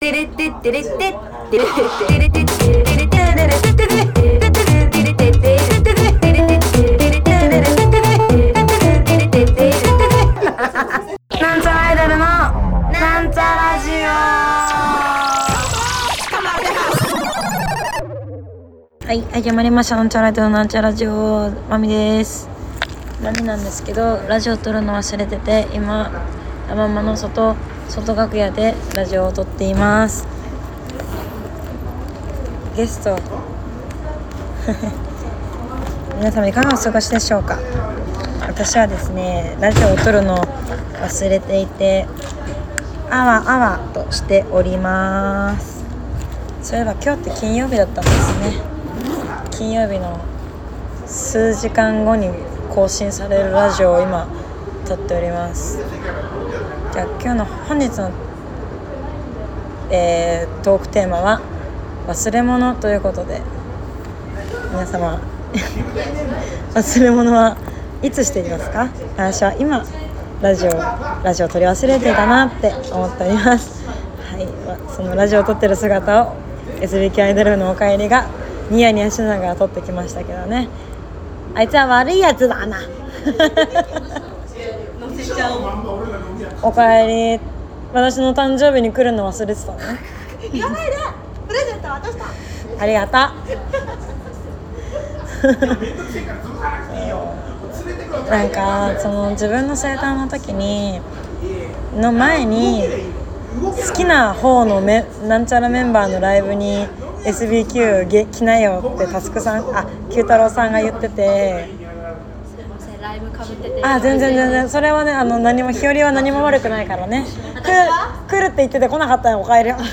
La.mama の外楽屋回で〜す